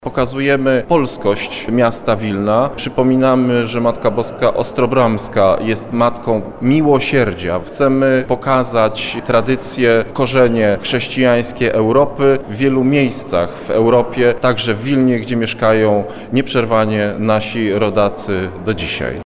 – O znaczeniu wystawy mówi Radiu Lublin jeden z jej inicjatorów, europoseł Mirosław Piotrowski.